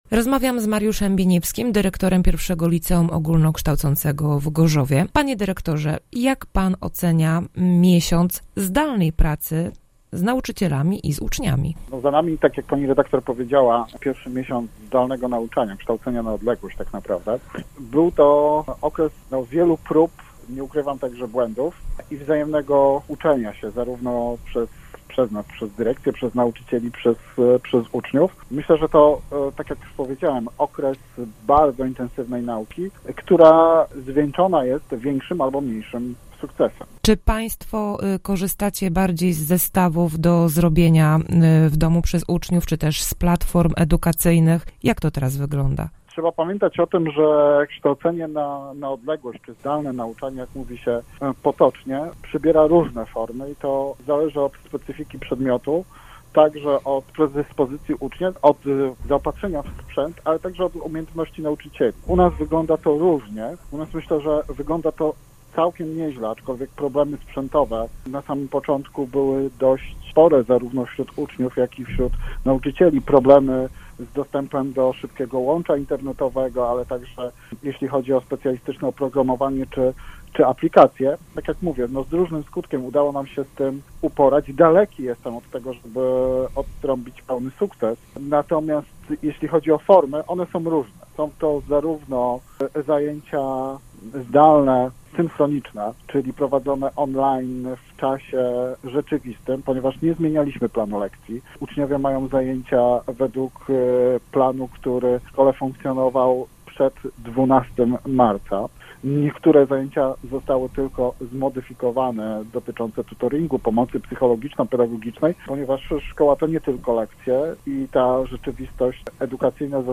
Cała rozmowa